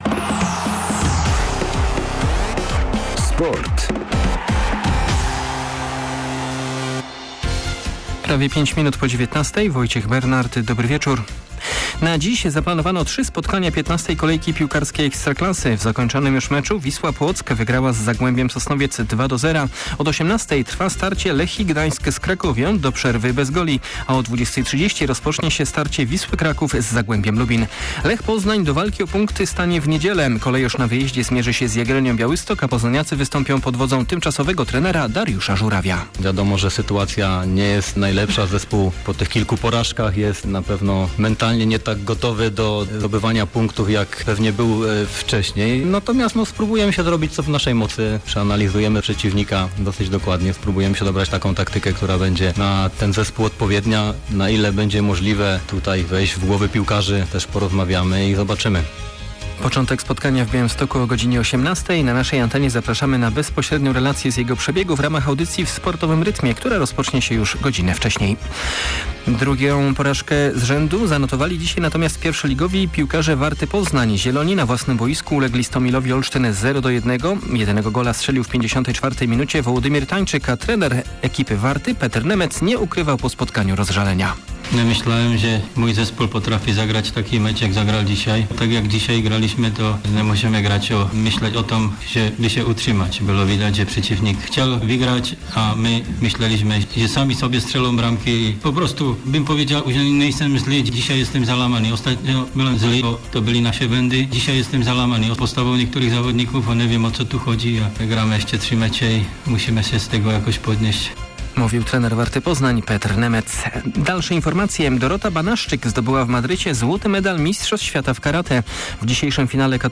10.11. SERWIS SPORTOWY GODZ. 19:05